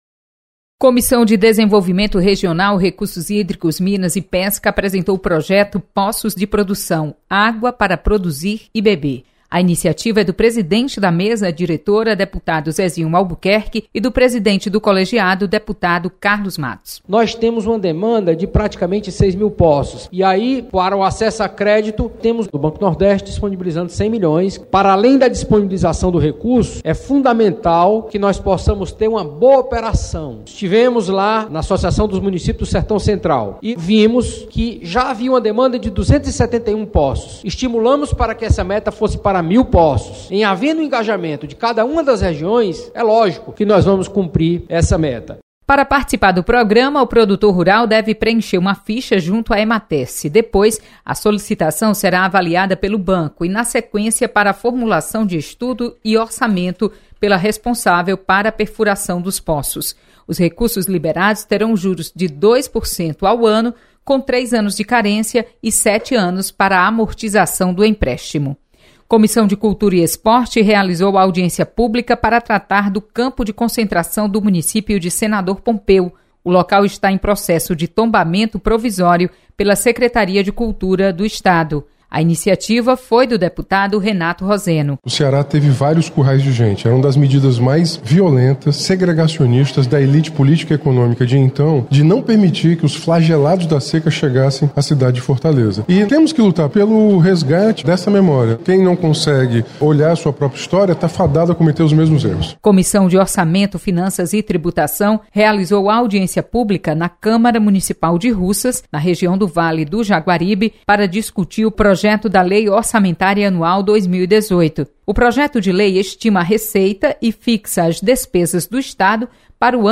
Acompanhe resumo das comissões técnicas da Assembleia Legislativa com a repórter